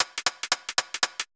Index of /musicradar/ultimate-hihat-samples/175bpm
UHH_ElectroHatC_175-03.wav